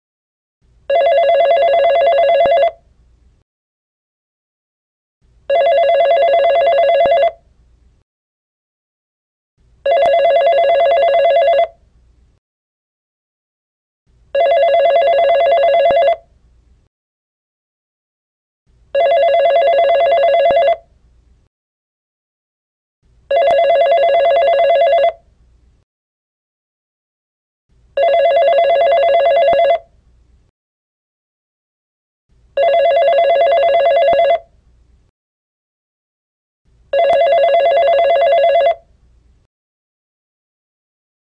Phone Ring
Category: Sound FX   Right: Personal